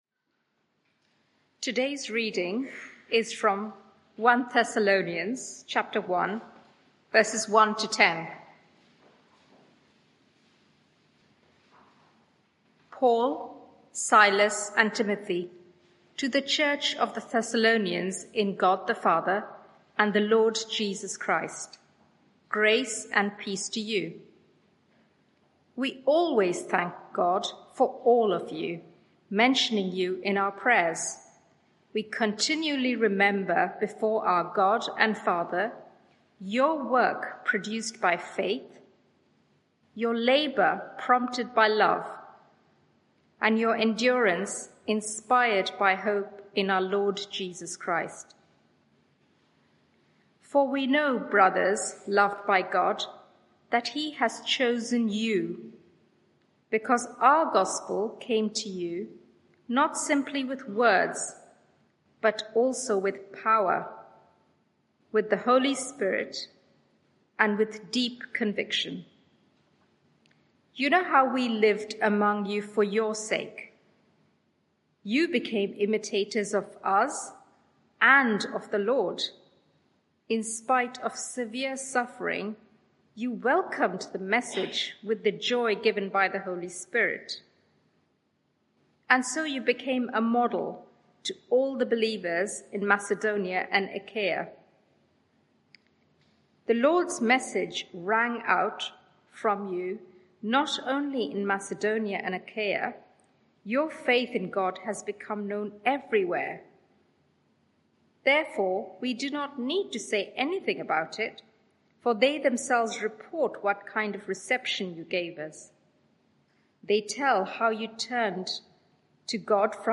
Media for 6:30pm Service on Sun 30th Jul 2023 18:30
Sermon (audio)